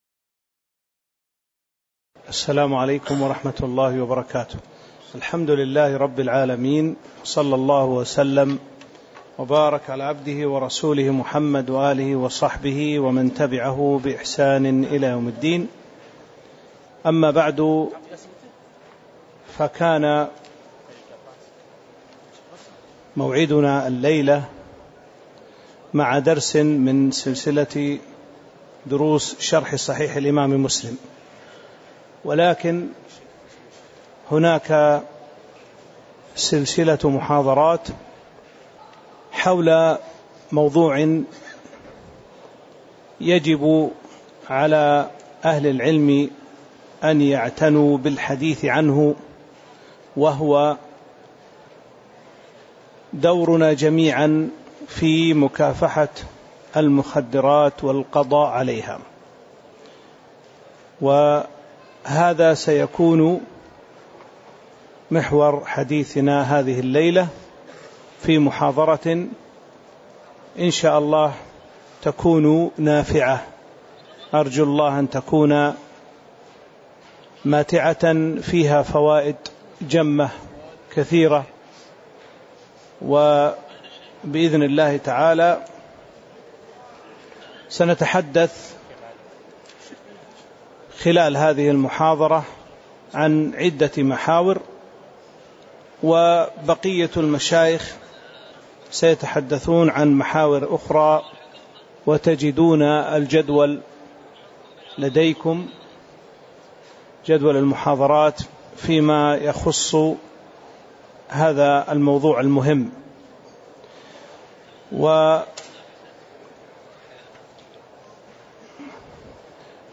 تاريخ النشر ١٧ شوال ١٤٤٤ هـ المكان: المسجد النبوي الشيخ